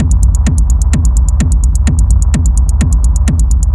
• hard minimal sample kick.wav
hard_minimal_sample_kick_ZUK.wav